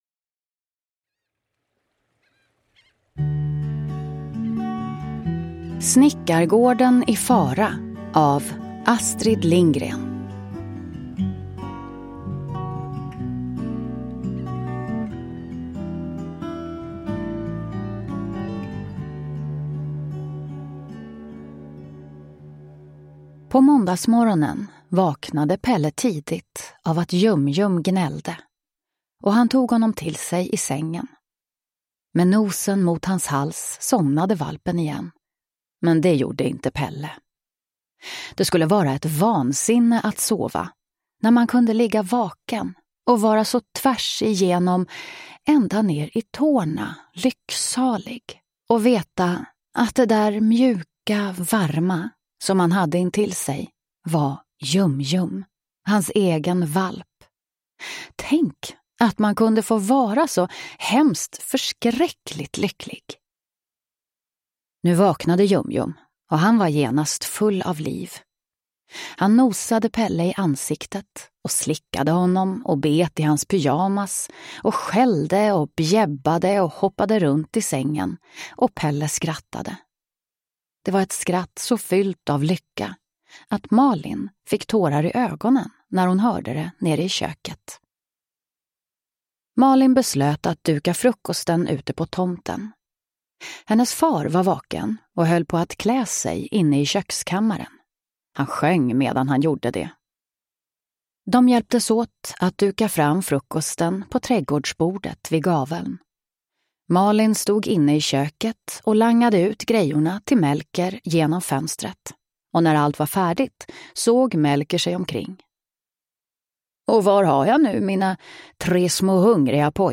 Snickargården i fara – Ljudbok